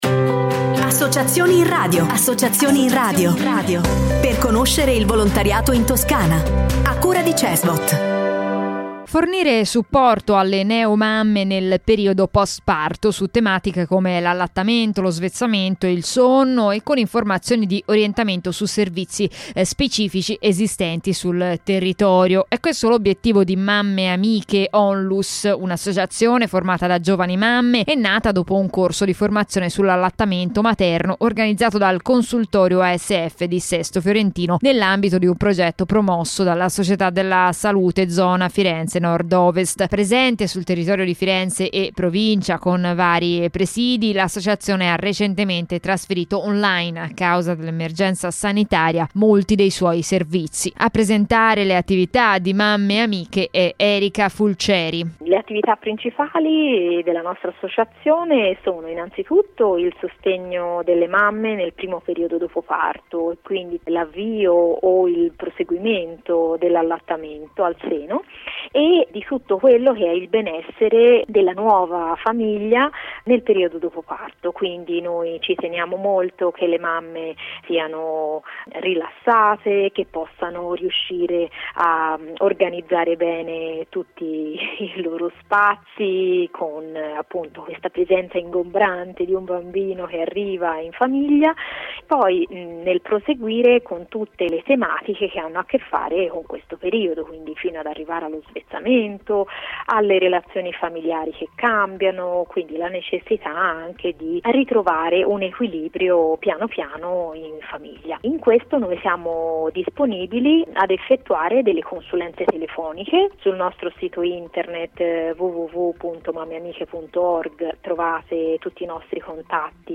Nella settimana dal 15 al 21 novembre 2021 l’Associazione MammeAmiche ha avuto la possibilità di andare in onda sulle frequenze di Controradio (frequenze) e Novaradio (frequenze) per presentare i propri servizi e incontri.